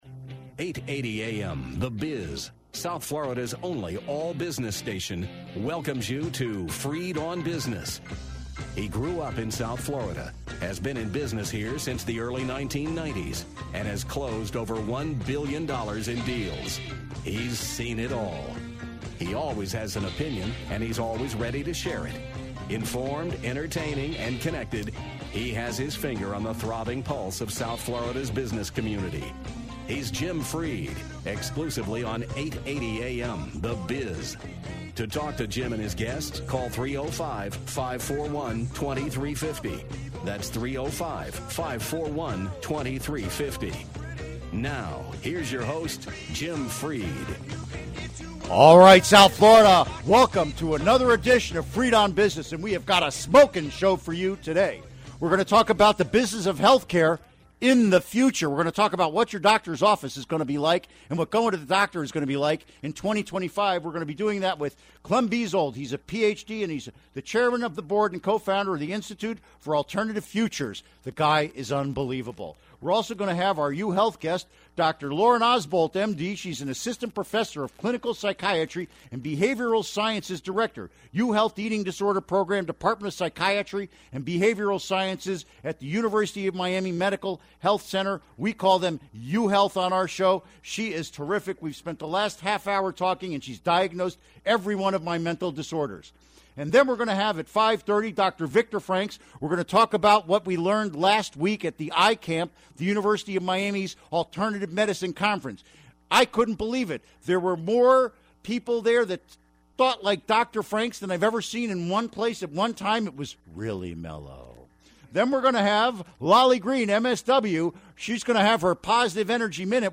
Listener E mails and Business Talk